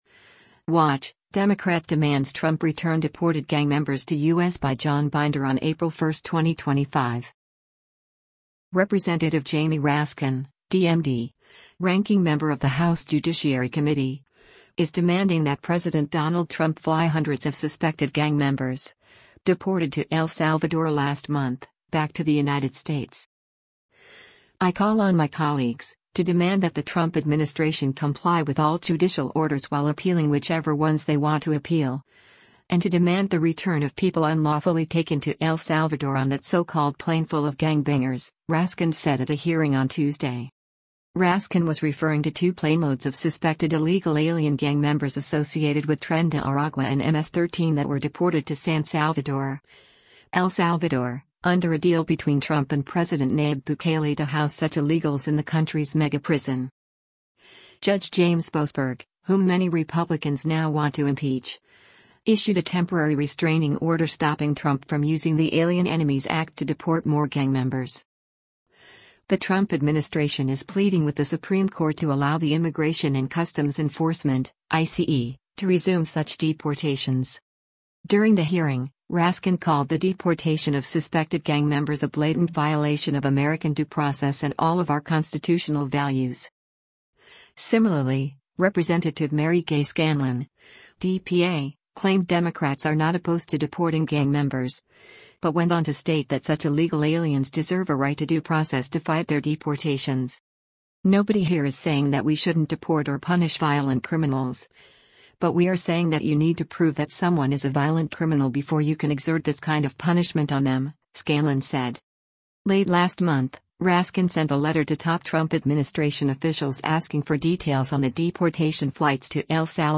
During the hearing, Raskin called the deportation of suspected gang members a “blatant violation of American due process and all of our constitutional values.”